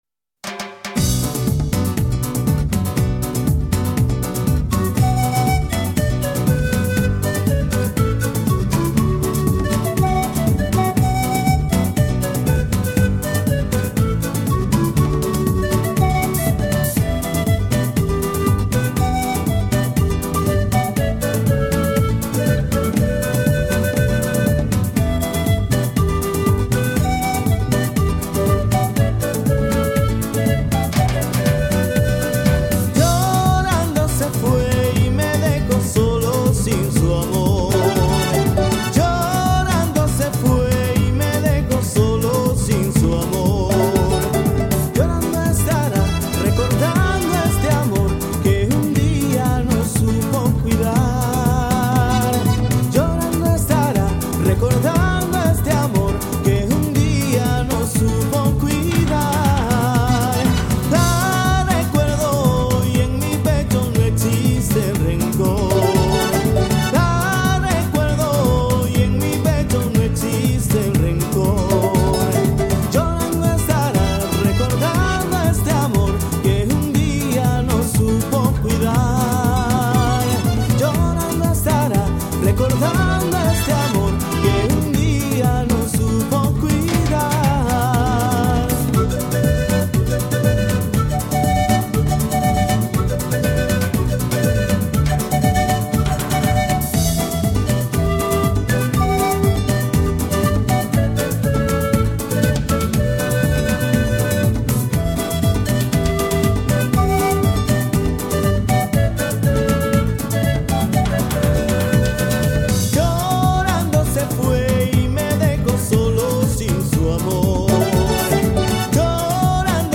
Жанр: Ethnic, folk, instrumental